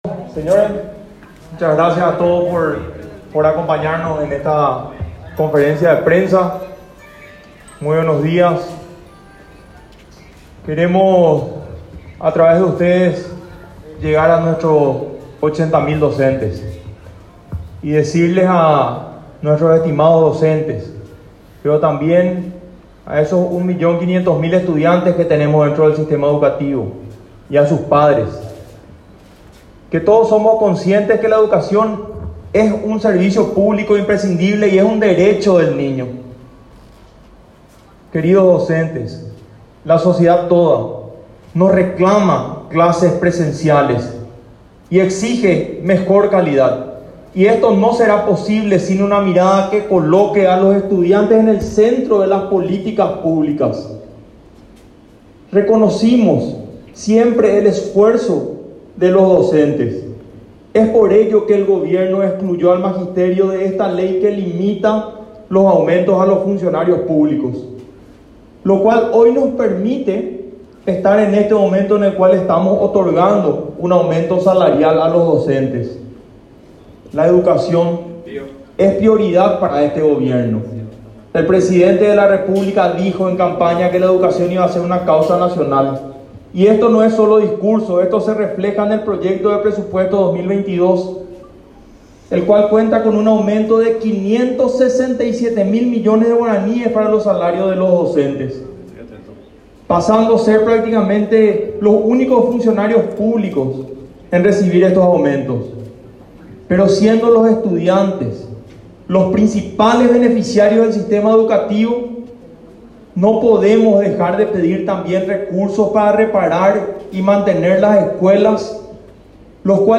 15-CONFERENCIA-DE-PRENSA-JUAN-MANUEL-BRUNETTI.mp3